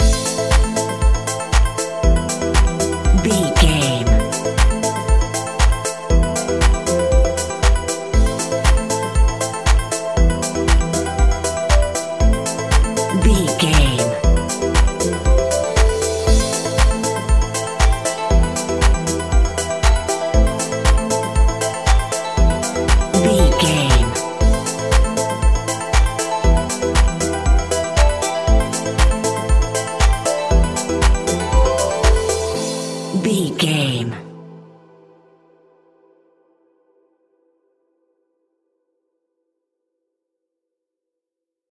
Aeolian/Minor
dark
futuristic
epic
groovy
drum machine
synthesiser
electric piano
house
electro house
funky house
synth leads
synth bass